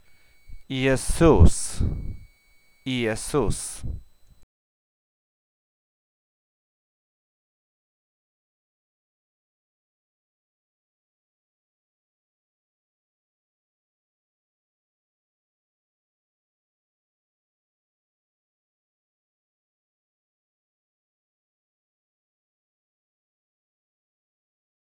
Z wymowy wynika, że "ie" wymawiamy i-e (nie "je") i że sylabą akcentowaną jest "sus".
wymowa_Jezus.wav